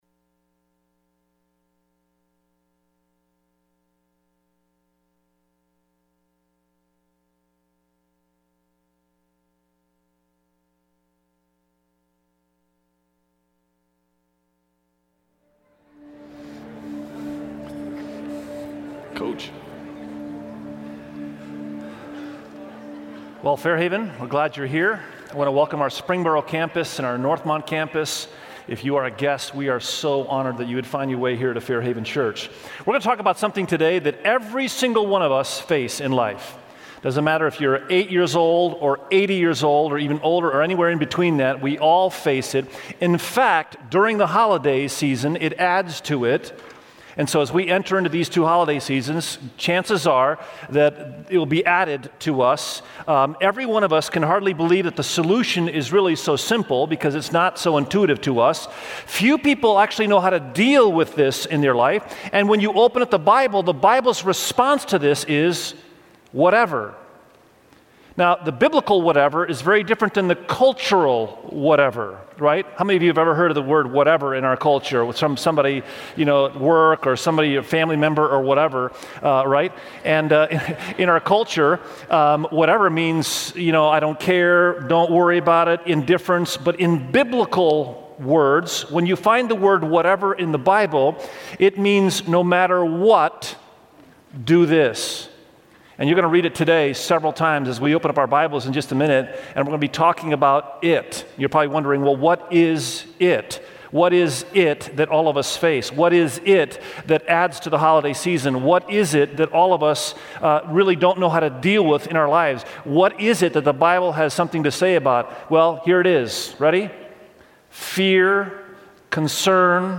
Check out Whatever, a sermon series at Fairhaven Church.